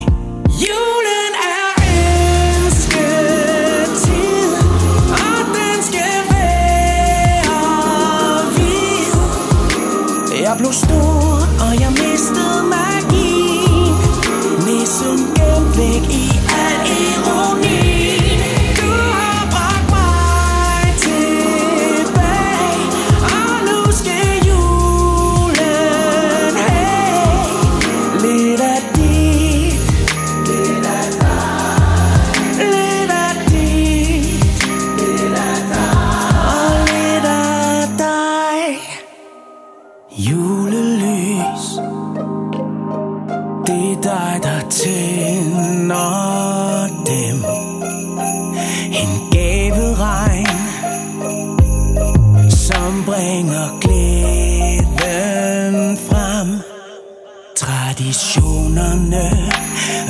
Pop
Vokal